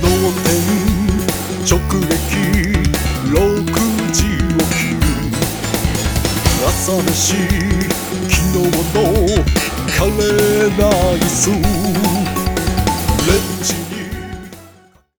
(A)オフラインミックスした場合